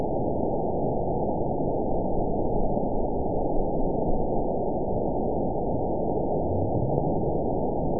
event 920534 date 03/28/24 time 22:10:43 GMT (1 year, 2 months ago) score 9.64 location TSS-AB03 detected by nrw target species NRW annotations +NRW Spectrogram: Frequency (kHz) vs. Time (s) audio not available .wav